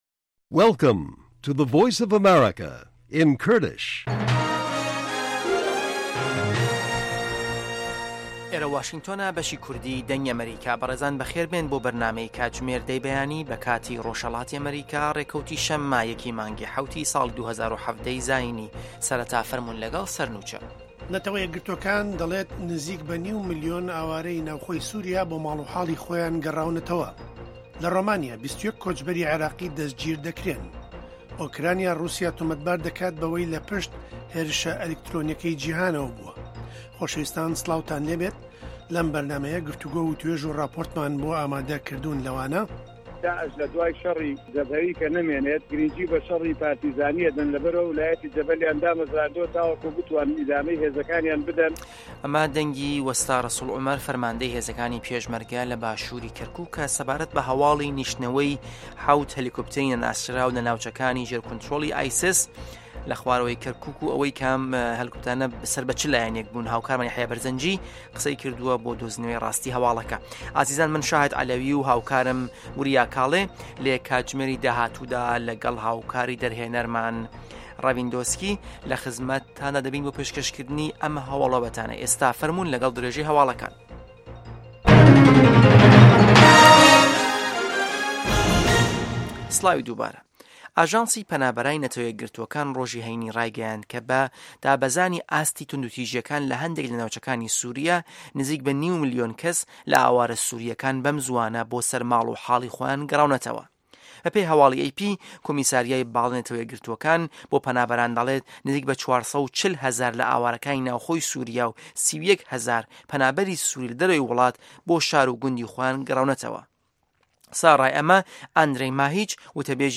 هه‌واڵه‌کان ، ڕاپـۆرت، وتووێژ.